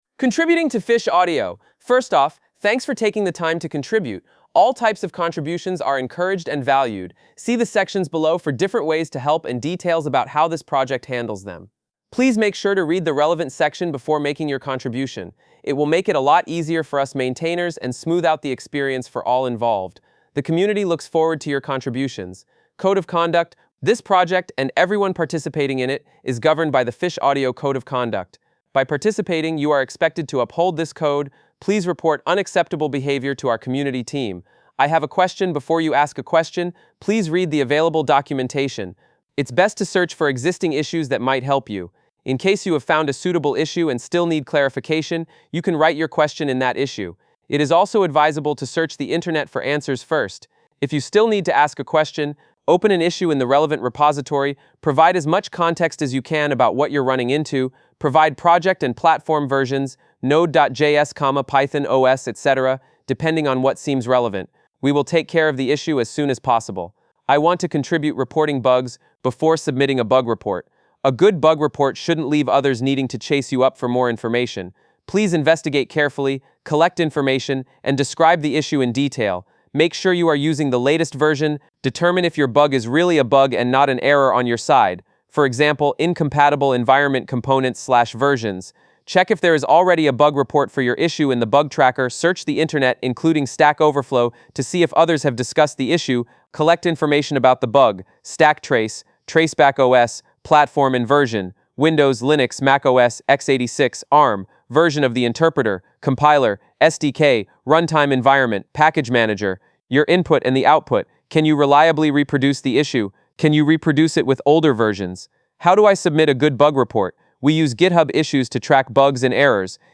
" }, { "id": "802e3bc2b27e49c2995d23ef70e6ac89", "name": "Energetic Male", "url": "